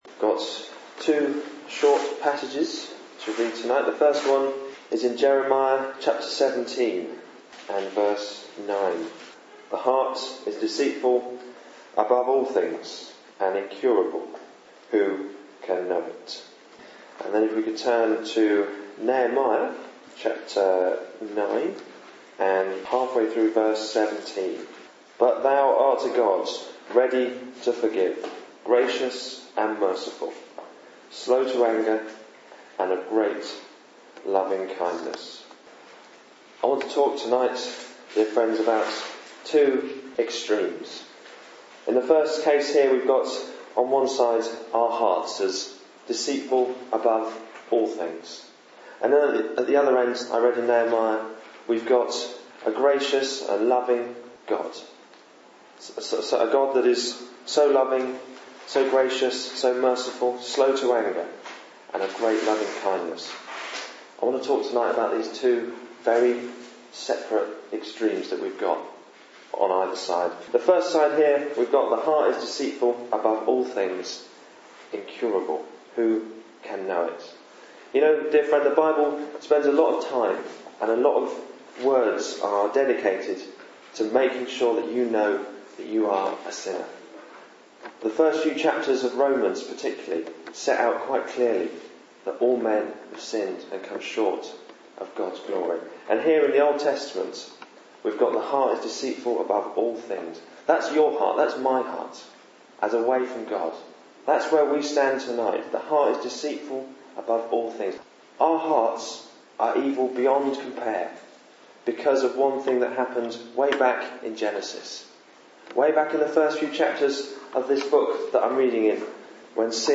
You will hear of two extremes in this Gospel preaching. The first one referring to the heart of mankind is deceitfully wicked and the second one referring to God who is rich in love and mercy.